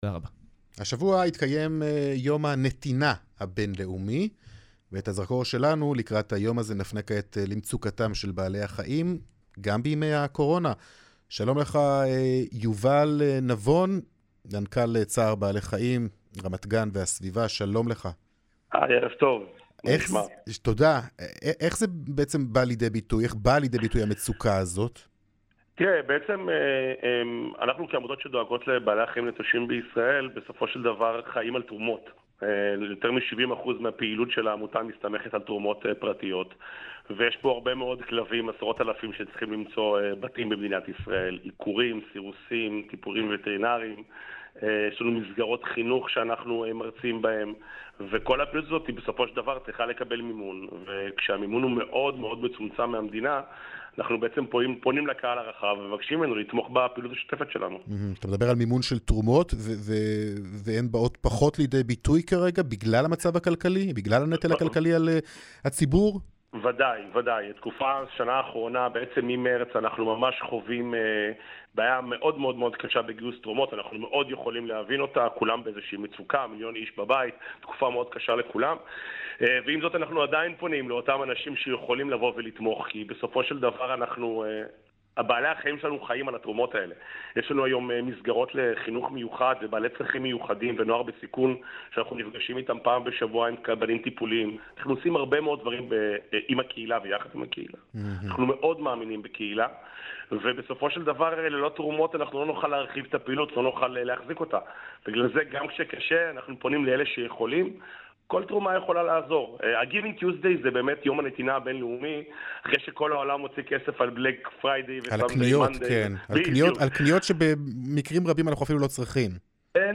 בראיון לרשת ב' לכבוד ה-GIVING TUESDAY